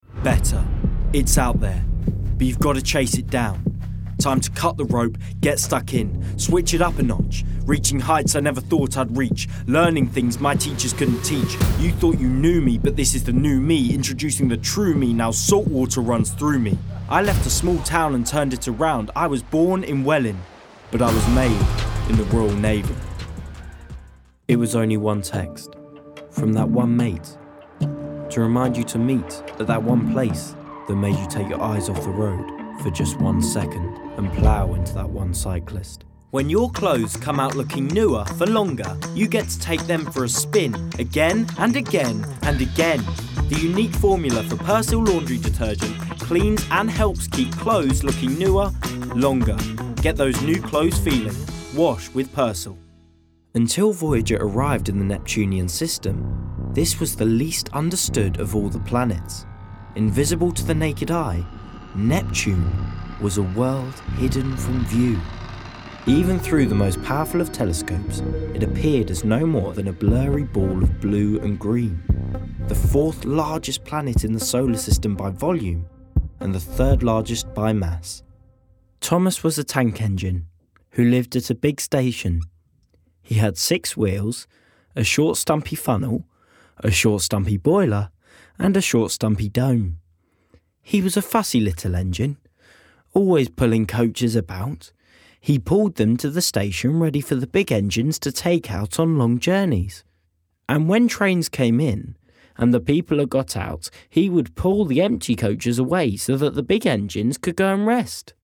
Native voice:
London
Voicereel: